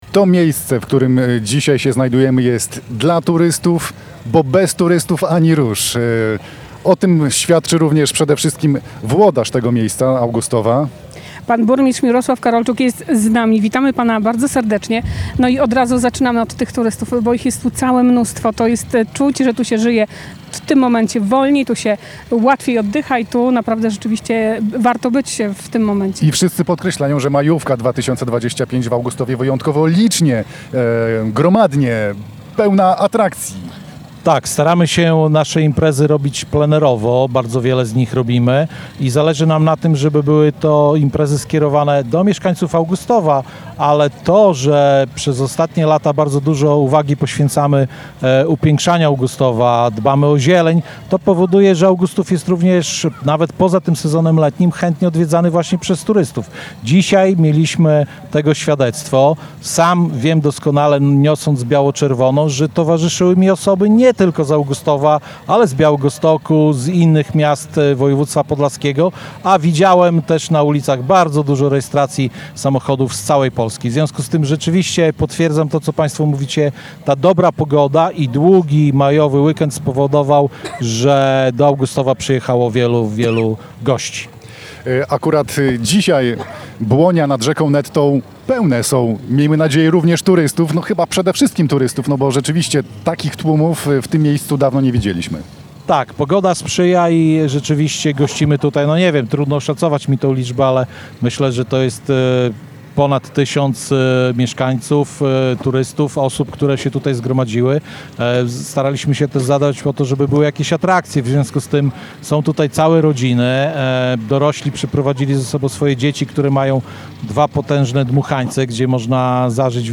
W piątek (2.05) Polskie Radio Białystok nadawało na żywo z serca letniej stolicy Polski, czyli Augustowa. Plenerowe studio trwało od 14:00 do 18:30 na Błoniach nad Nettą, a antenowy czas wypełnialiśmy ciekawymi konkursami i rozmowami z zaproszonymi gośćmi.
Rozmowa